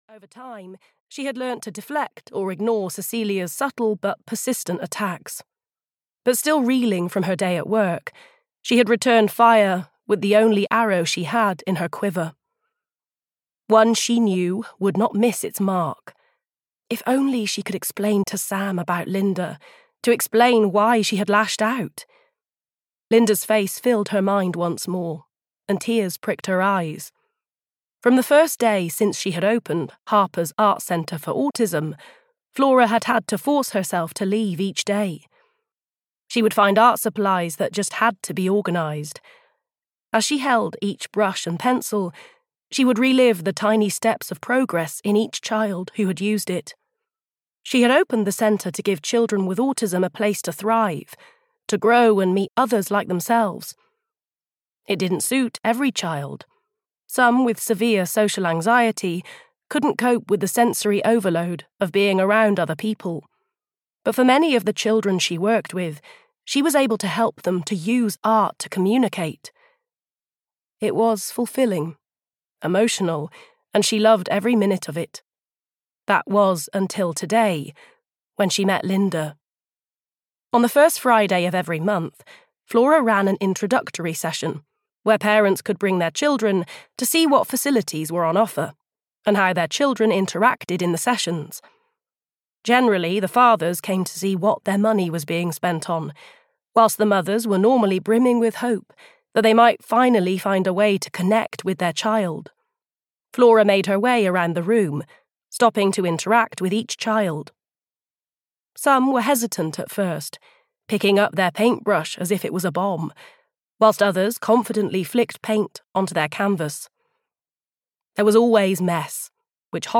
Save Her (EN) audiokniha
Ukázka z knihy